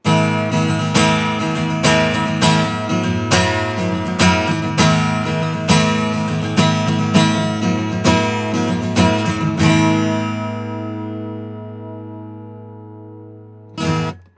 AT４０５０は、ラージダイヤフラムのコンデンサーマイクになります。
音質は、癖がなくナチュラルでフラットな印象です。
アコースティック・ギター
4050-アコギ.wav